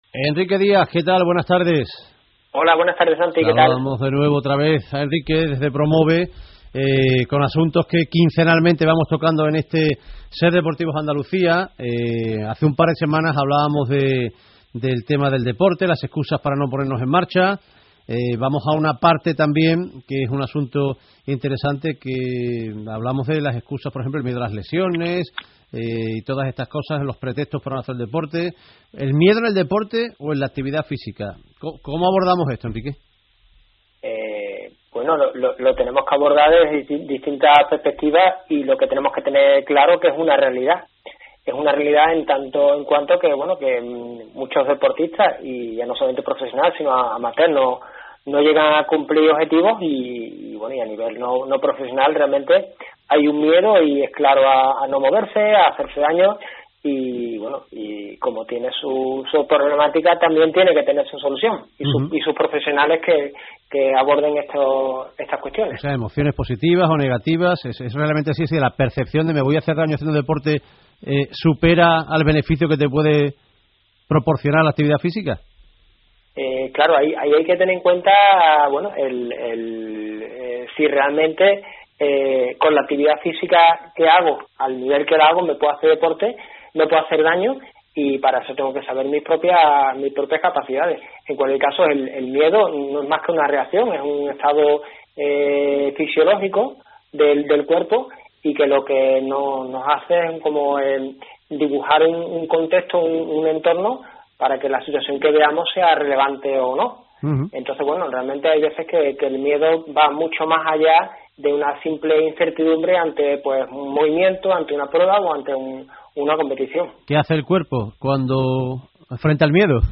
La entrevista del audio que puedes escuchar más adelante pertenece al programa Ser Deportivos Andalucía y fue emitido el pasado domingo 23 de mayo.